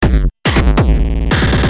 标签： 140 bpm Glitch Loops Drum Loops 295.55 KB wav Key : Unknown
声道立体声